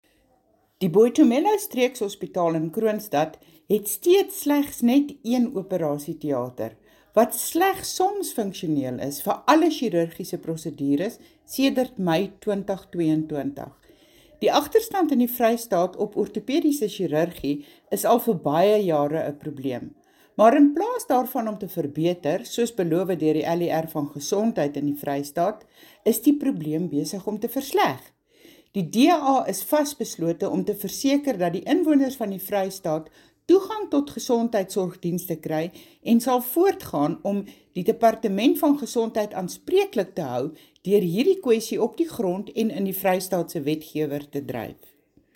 Afrikaans soundbites by Mariette Pittaway MPL and